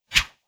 Close Combat Swing Sound 42.wav